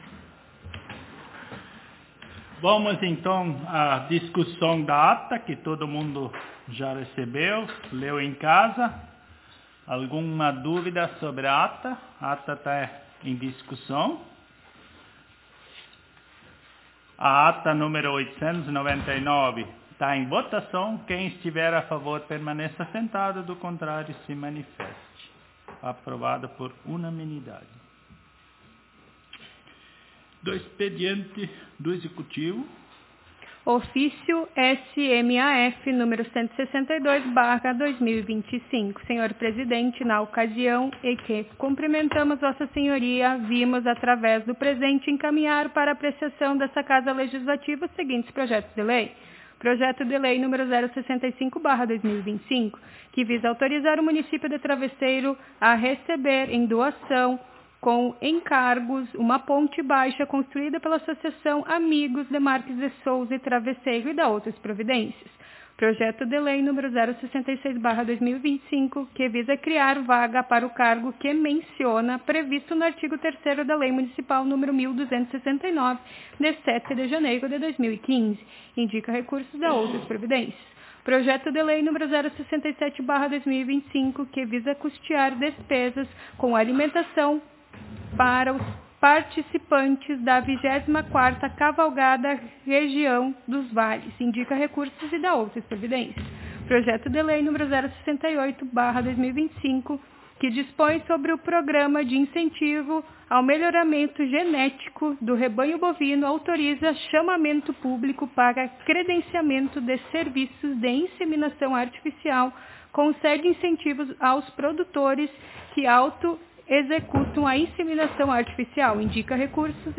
Ao 1º (primeiro) dias do mês de setembro do ano de 2025 (dois mil e vinte e cinco), na Sala de Sessões da Câmara Municipal de Vereadores de Travesseiro/RS, realizou-se a Décima Sétima Sessão Ordinária da Legislatura 2025-2028, sob a Presidência do Vereado